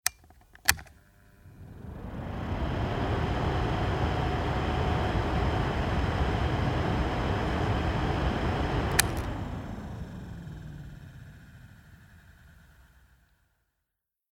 "HL1" table fan
Level 2